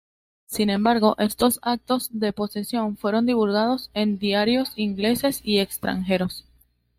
/poseˈsjon/